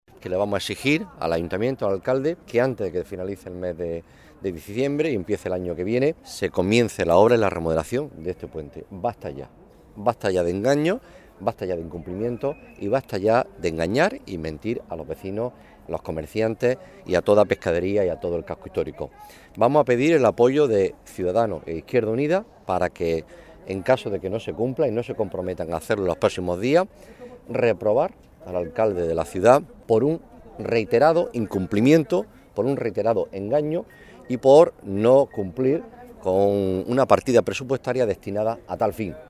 El portavoz del PSOE en el Ayuntamiento de Almería, Juan Carlos Pérez Navas